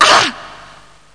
femouch3.mp3